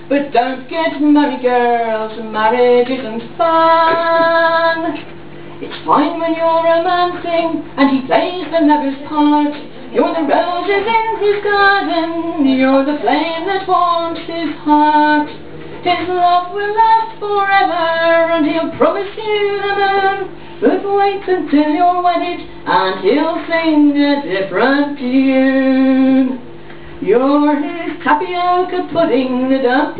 Pub Musicians (8)
pub-musicians-8.wav